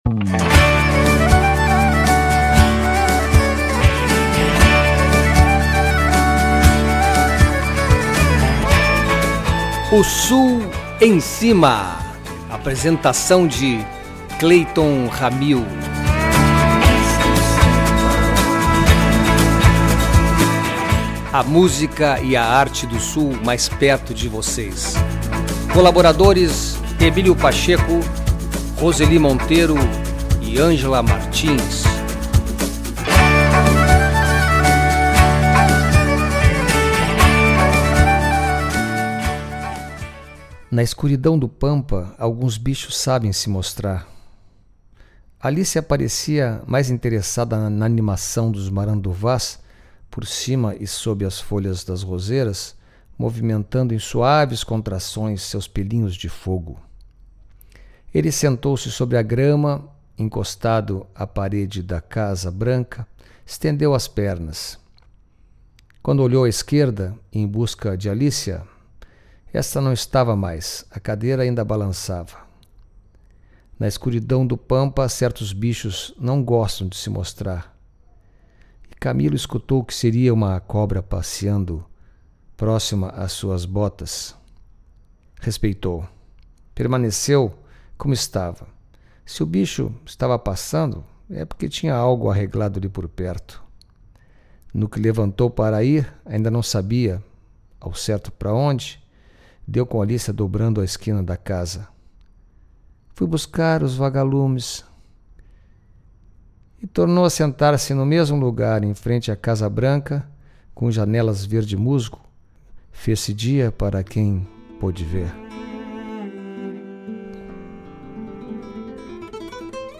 Esse programa traz um apetitoso desafio: a música instrumental.
O Sul Em Cima 11 é um especial de instrumentos, sem voz. É poesia ditada em sons e timbres de várias vertentes.